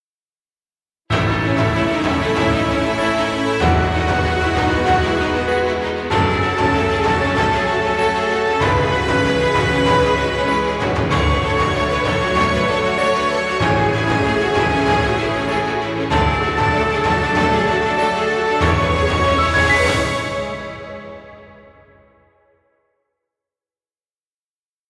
Epic music, exciting intro, or battle scenes.
Trailer music.